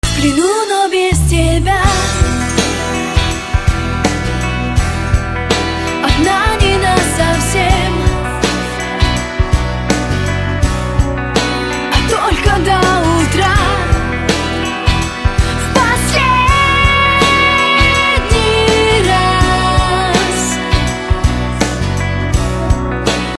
Попса [41]
Pop